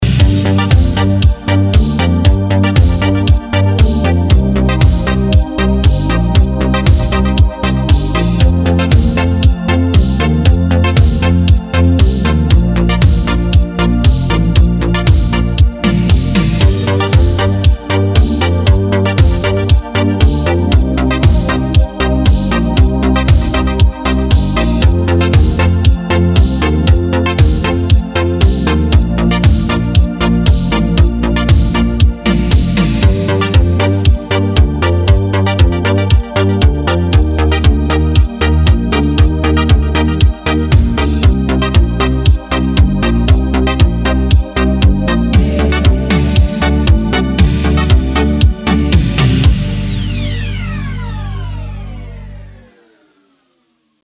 • Качество: 320, Stereo
ритмичные
диско
спокойные
без слов
инструментальные
Eurodance